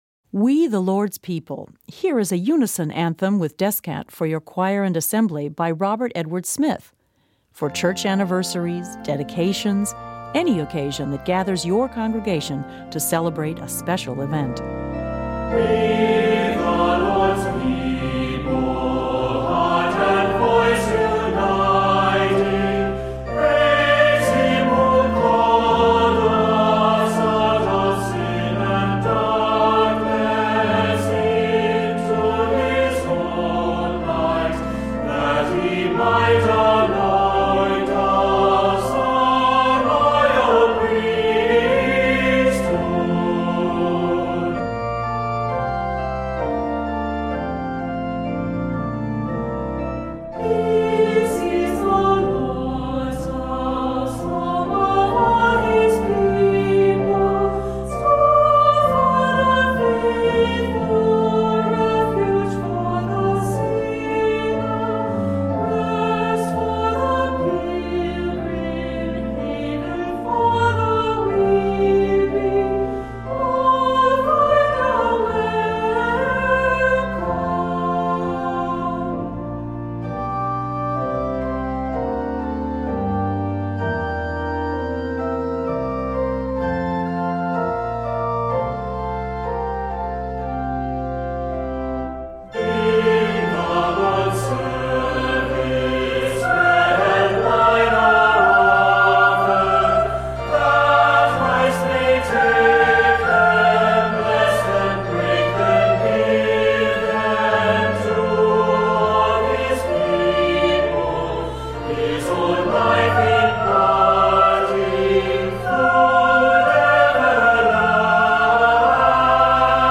Voicing: Assembly